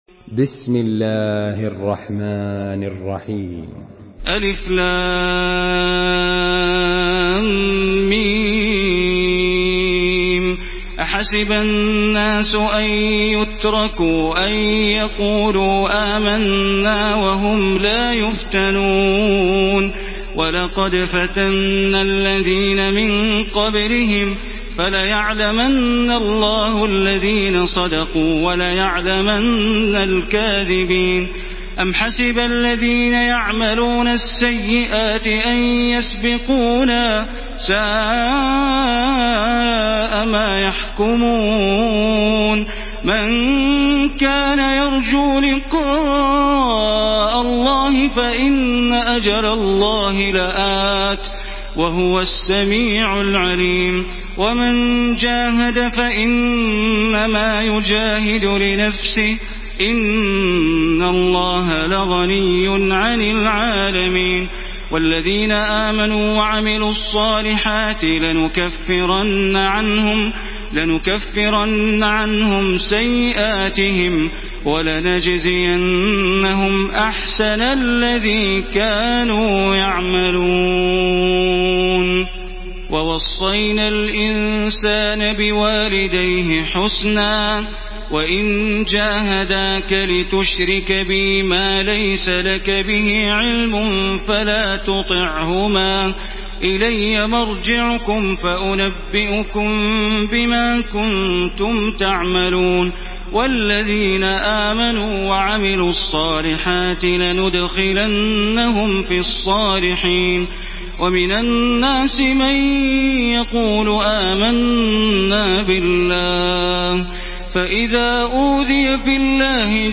Surah Ankebut MP3 by Makkah Taraweeh 1434 in Hafs An Asim narration.
Murattal Hafs An Asim